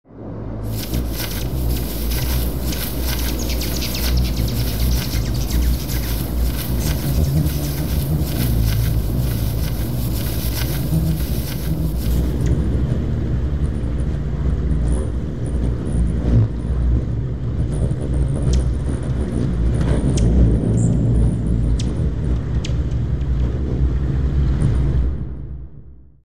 Slow-Motion-Bee-Sound.mp3